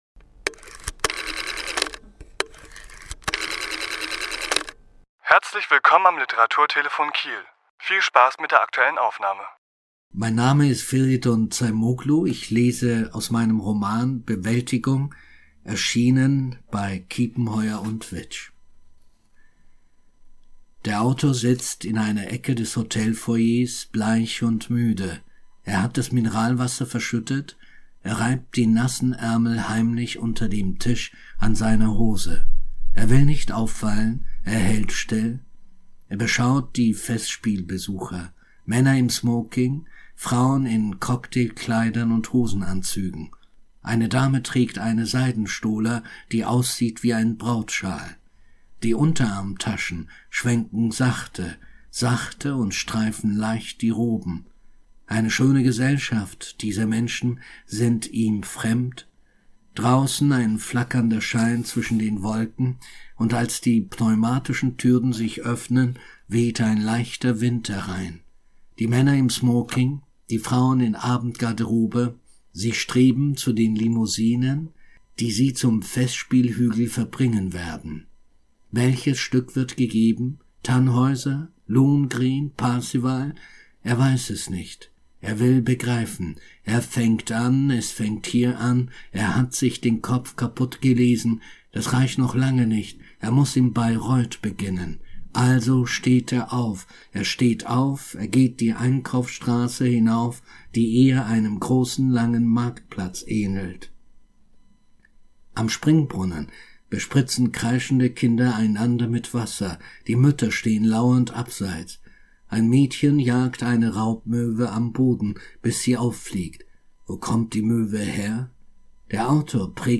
Autor*innen lesen aus ihren Werken
Die Aufnahme entstand am 28.09.2022 im Rahmen einer Lesung im Literaturhaus S.-H.